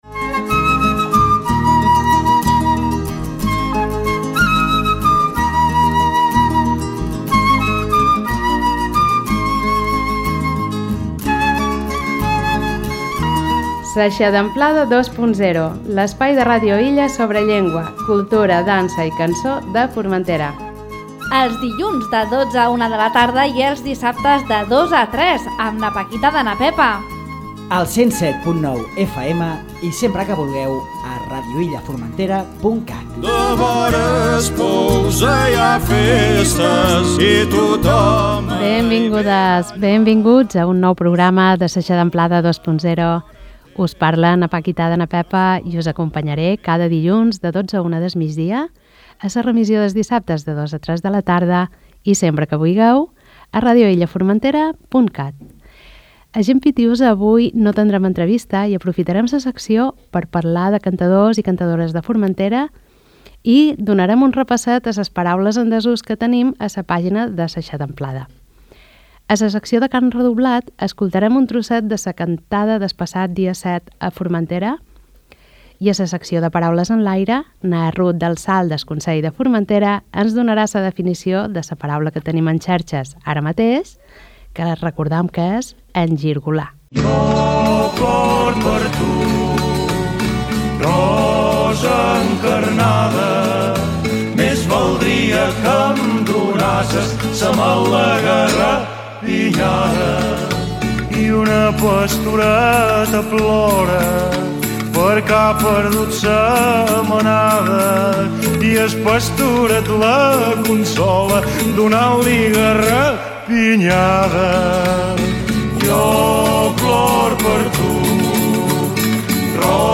ens ofereix una selecció dels fragments més destacables de la VIII Cantada Pagesa, celebrada el passat dia 7 de febrer a la Sala de Cultura.